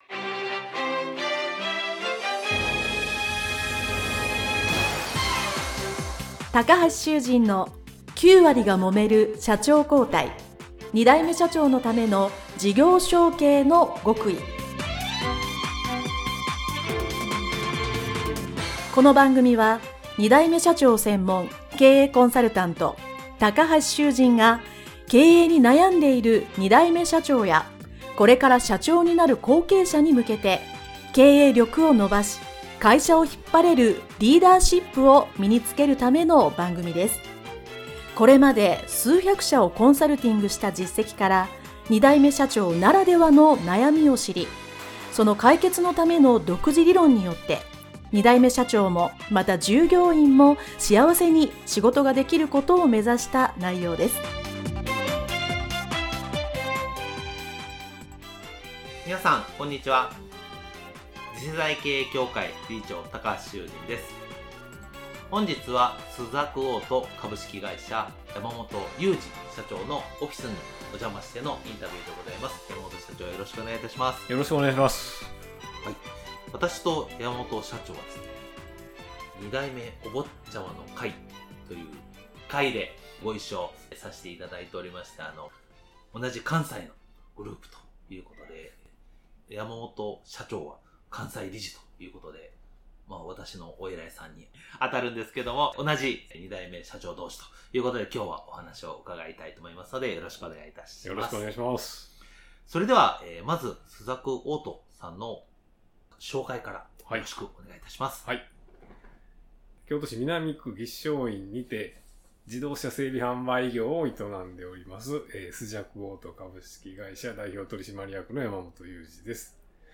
【インタビュー前編】